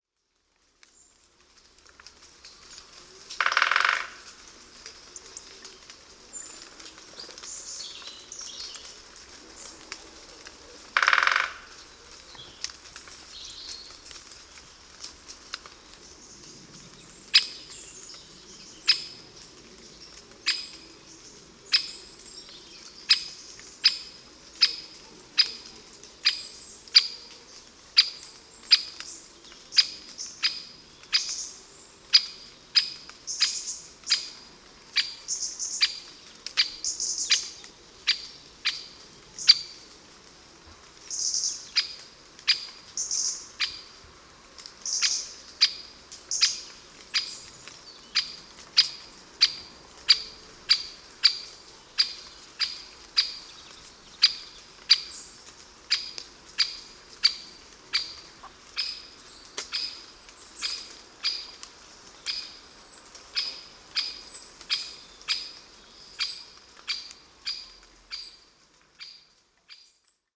Peto real
Bosques, arboredos
Canto